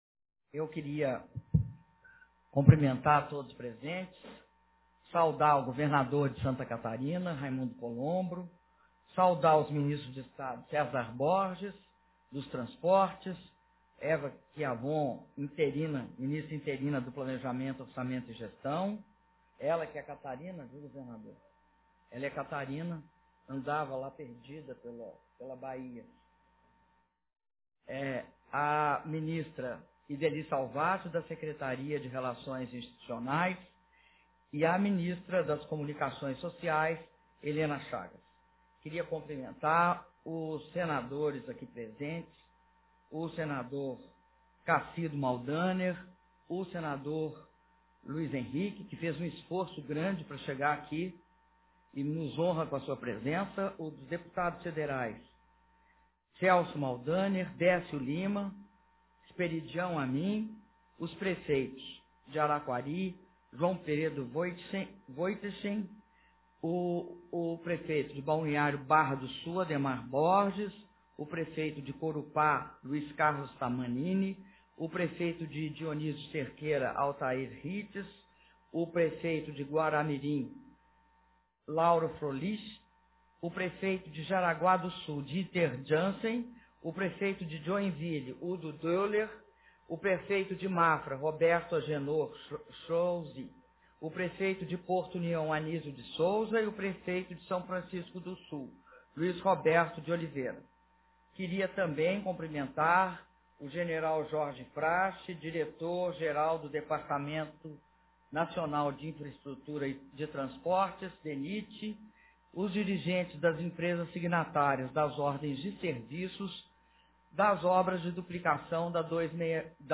Áudio do discurso da Presidenta da República, Dilma Rousseff, durante cerimônia de assinatura da ordem de serviço da duplicação da BR-280/SC